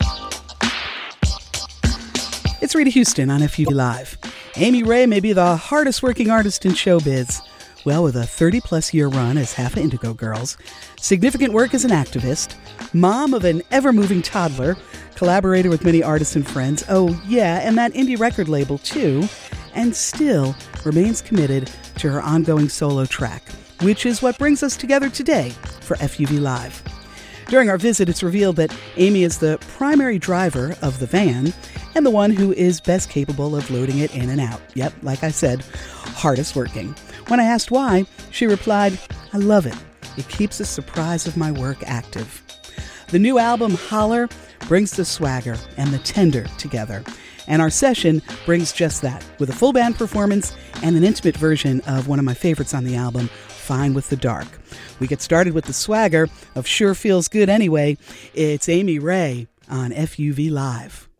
(recorded from a webcast)
01. intro (1:01)